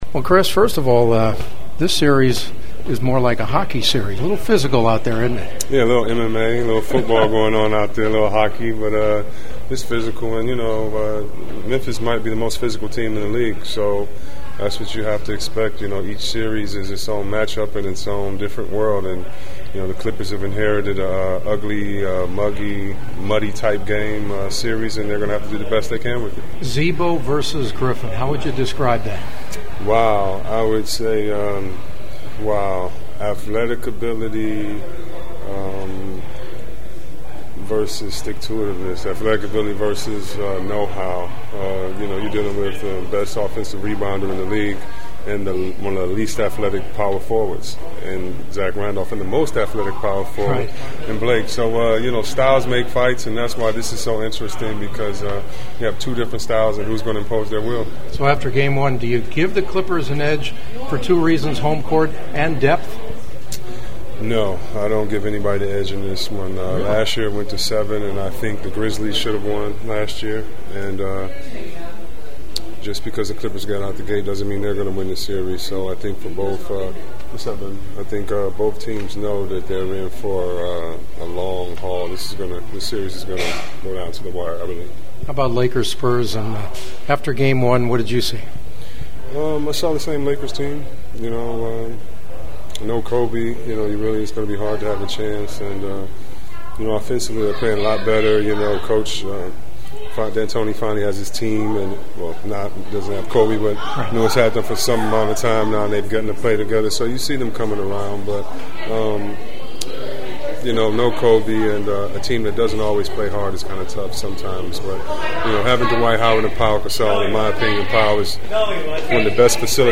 When I walked out of the Clippers locker room former NBA All-star Chris Webber was standing in the hallway and I shook his hand congratulating him on the start of his new career in broadcasting.